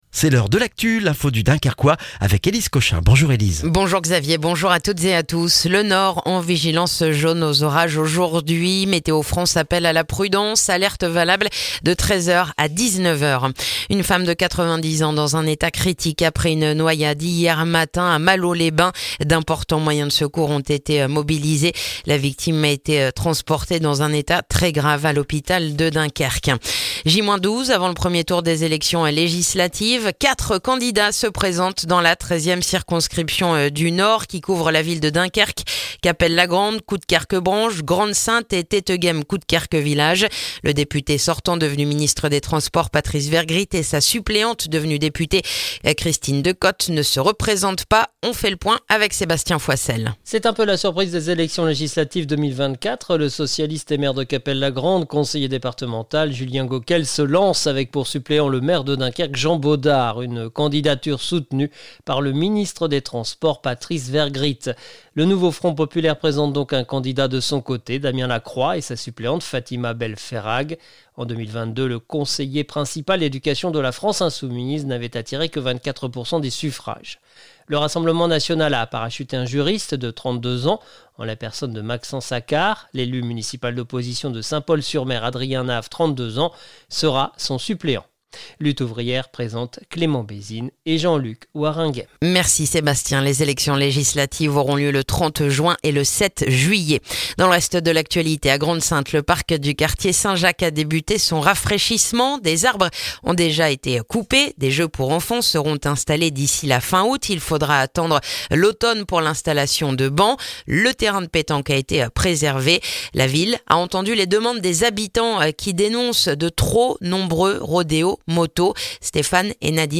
Le journal du mardi 18 juin dans le dunkerquois